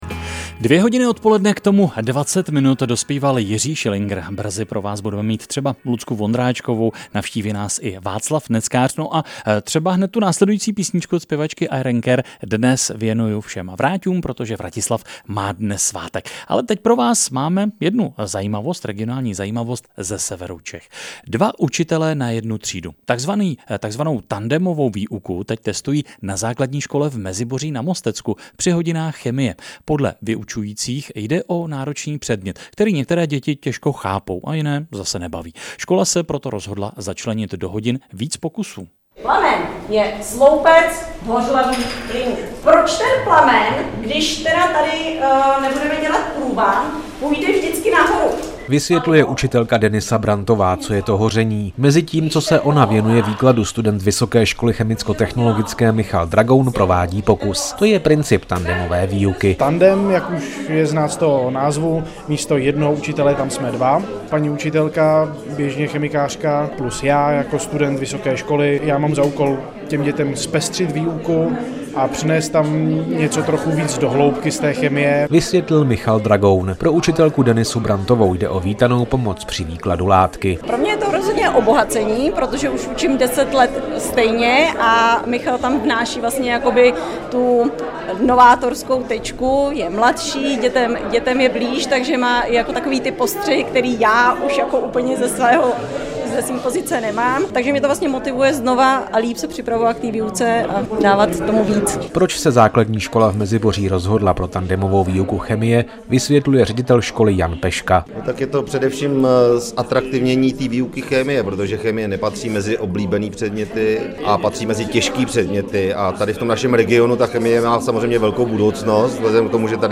Záznam z radiového vysílání si můžete stáhnout ZDE
chemie_radio.mp3